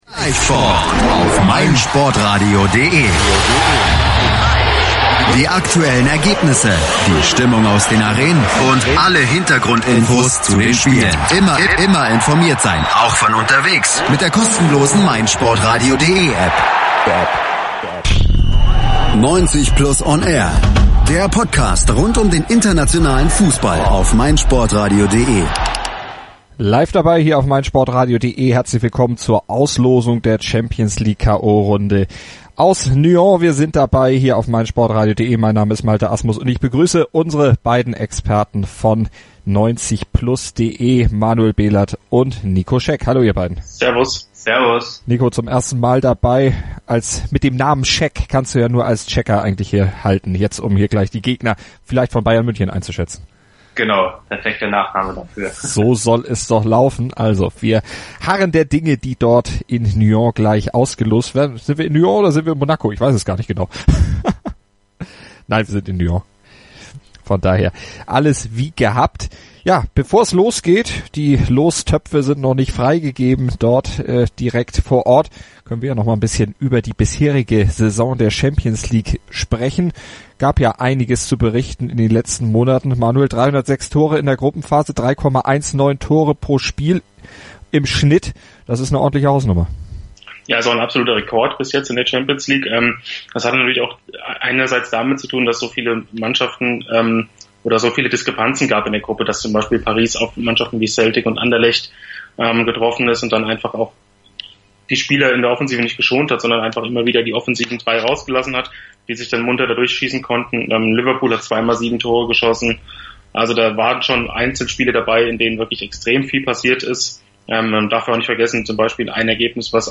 haben die Auslosung live analysiert und die einzelnen Partien eingeschätzt.